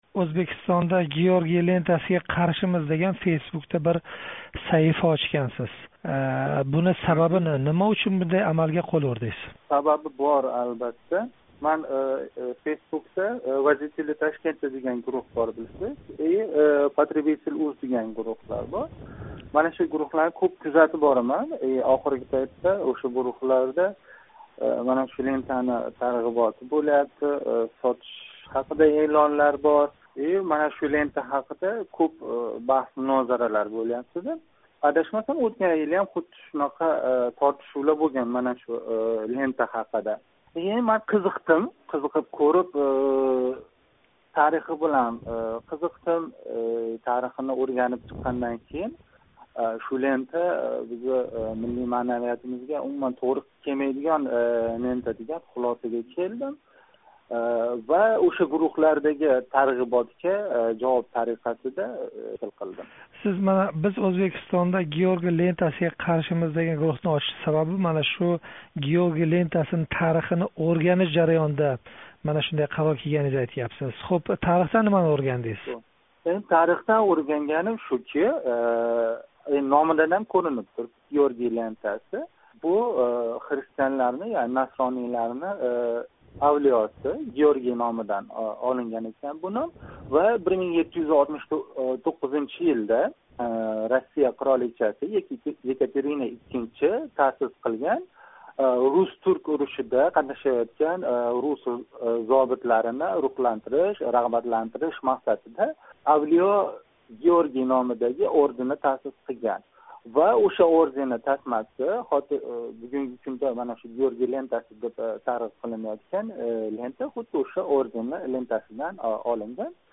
Журналист